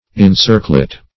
Search Result for " incirclet" : The Collaborative International Dictionary of English v.0.48: Incirclet \In*cir"clet\, n. [Cf. Encirclet .]